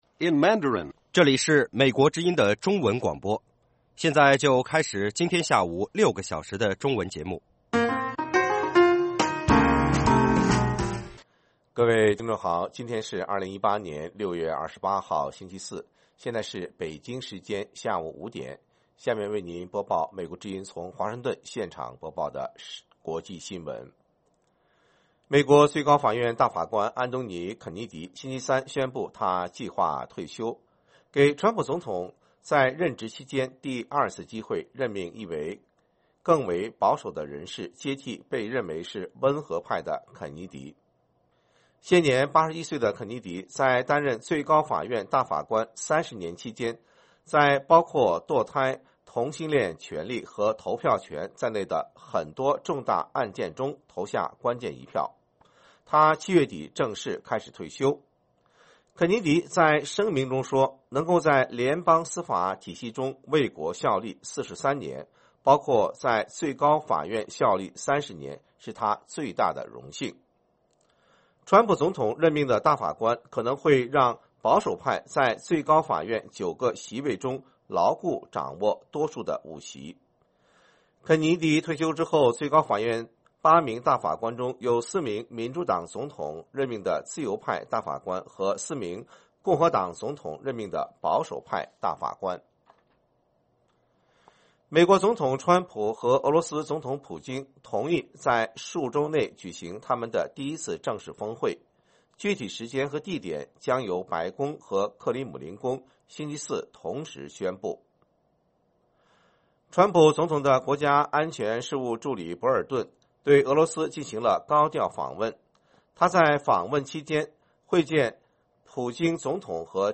北京时间下午5-6点广播节目。广播内容包括国际新闻，新动态英语，以及《时事大家谈》(重播)